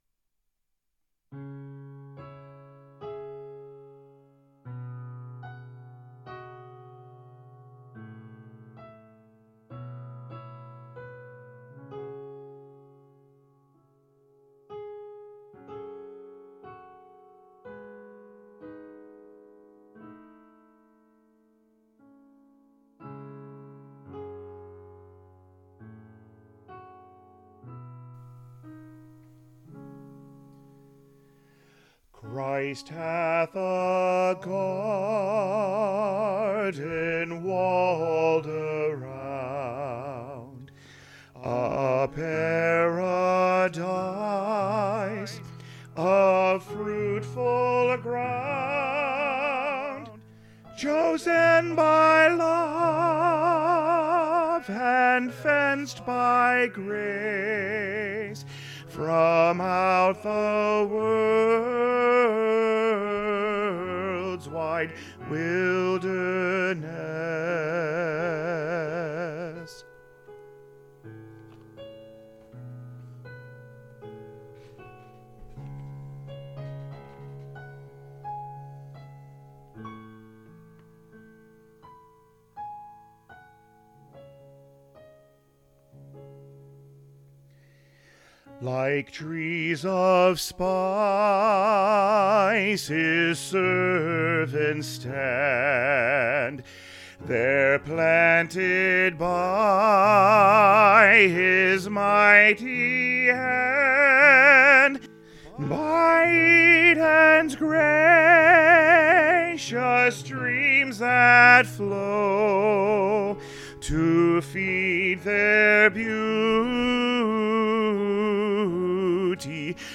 Choir Music Learning Recordings
Christ Hath a Garden - Tenor Emphasized All 4 Parts with The Tenor Part Emphasized